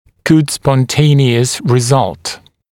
[gud spɔn’teɪnɪəs rɪ’zʌlt][гуд спон’тэйниэс ри’залт]благоприятный самопроизвольный результат